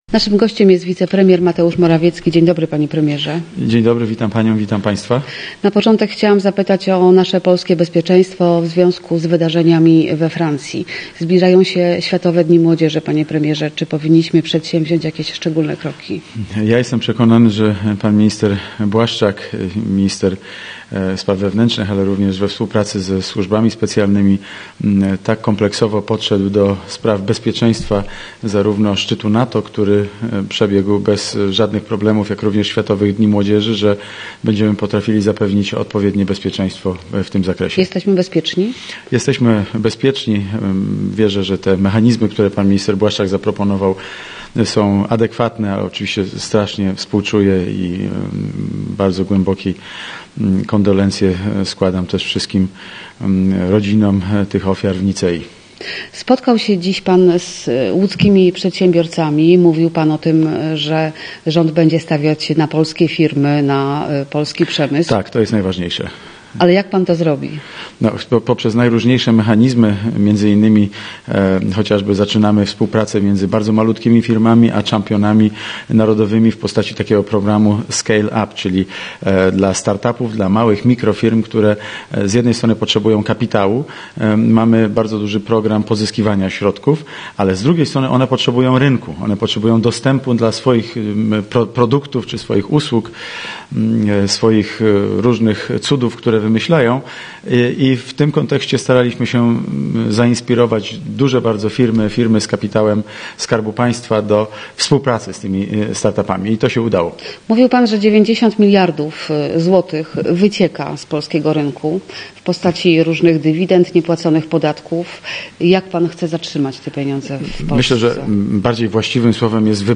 Posłuchaj rozmowy z Mateuszem Morawieckim: Nazwa Plik Autor Mateusz Morawiecki audio (m4a) audio (oga) ZDJĘCIA, NAGRANIA WIDEO, WIĘCEJ INFORMACJI Z ŁODZI I REGIONU ZNAJDZIESZ W DZIALE “WIADOMOŚCI”.